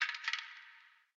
ambienturban_14.ogg